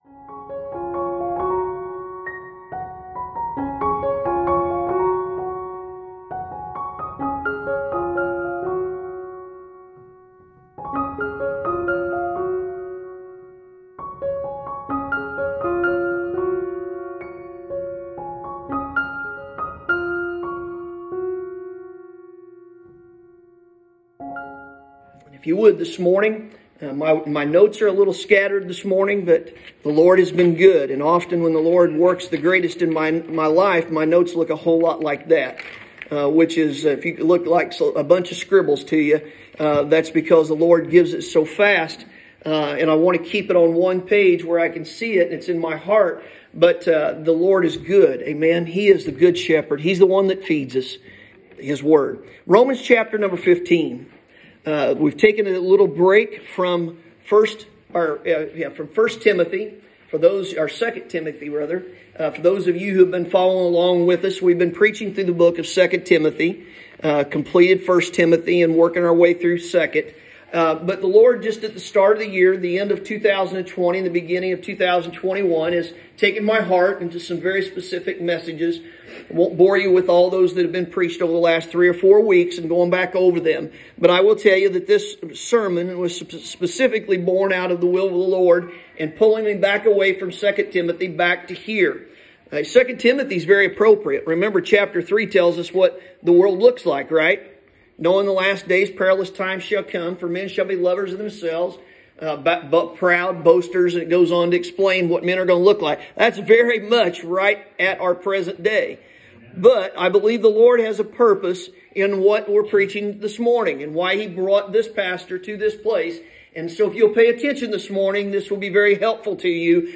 Sunday Morning – January 10, 2021